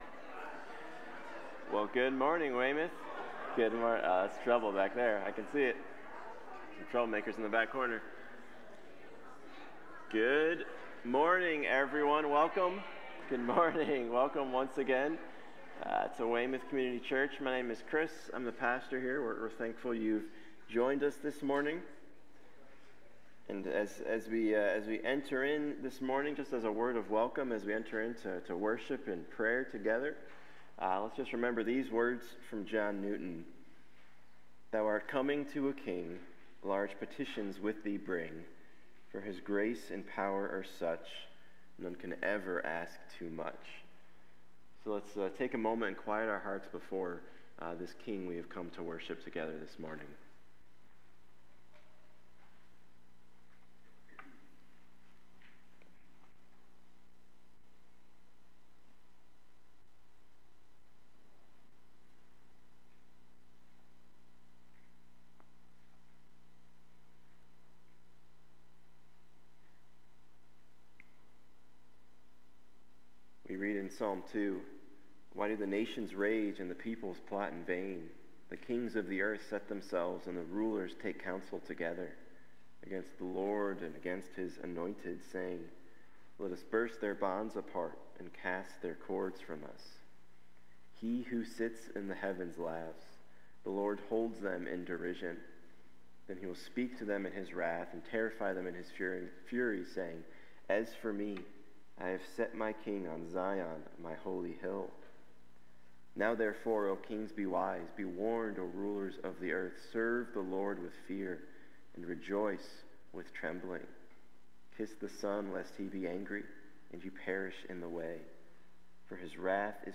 1 Peter 2:13-17 Service Type: Sunday Morning Submit to human authorities as free servants of God.